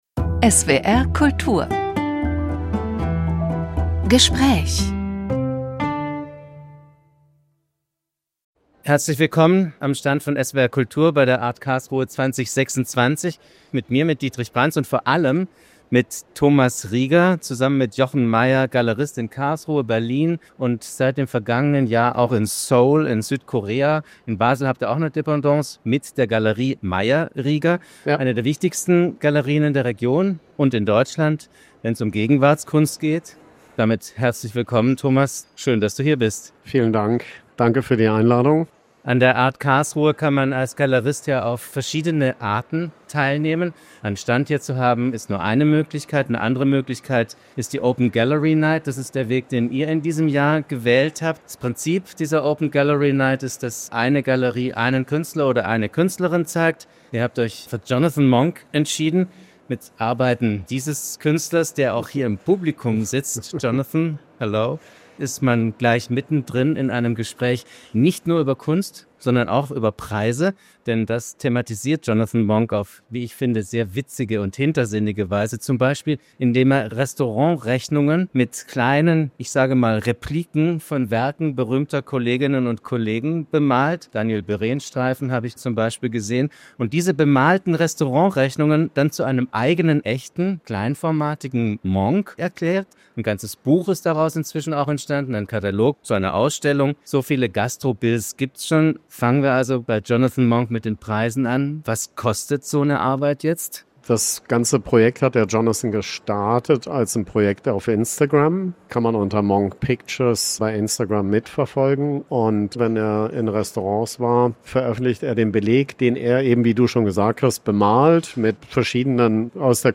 (Öffentliche Veranstaltung vom 7. Februar 2026 bei der art karlsruhe)